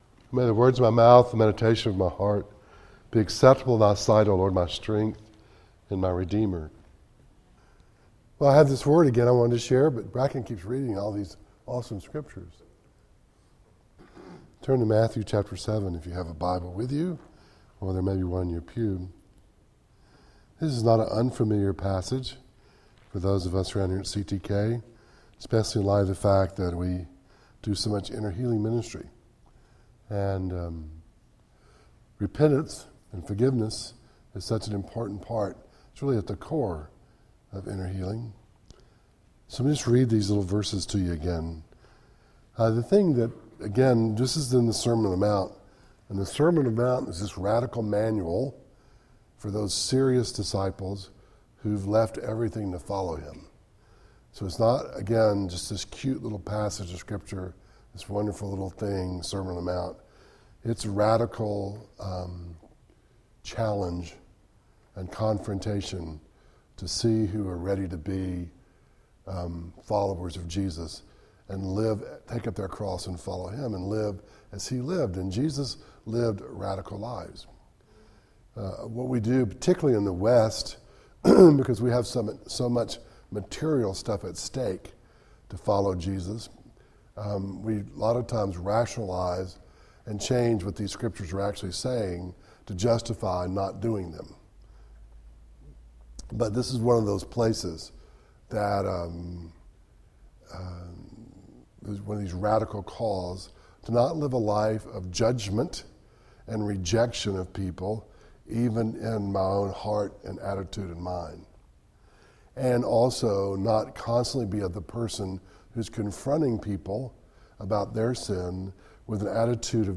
1 John 1:5-10 Service Type: Devotional